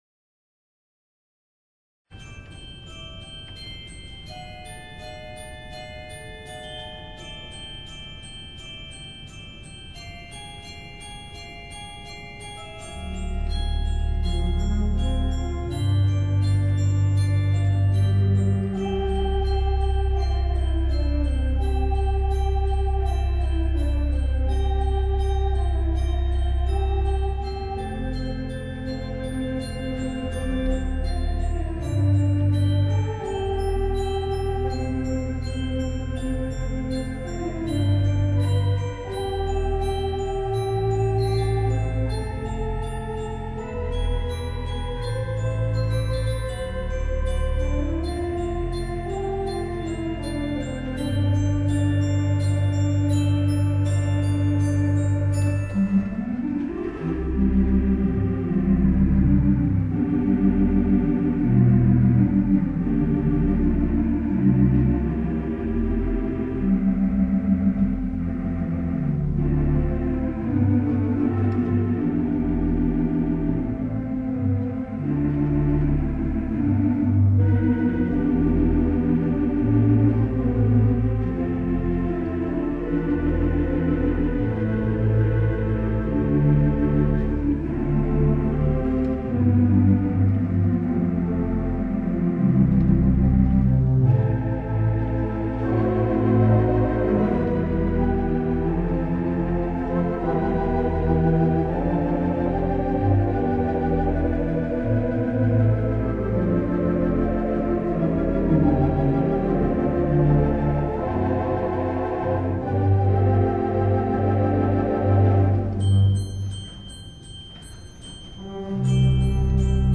The Mighty WurliTzer on the stage